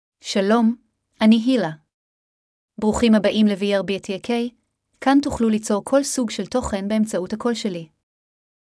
HilaFemale Hebrew AI voice
Hila is a female AI voice for Hebrew (Israel).
Voice sample
Listen to Hila's female Hebrew voice.
Hila delivers clear pronunciation with authentic Israel Hebrew intonation, making your content sound professionally produced.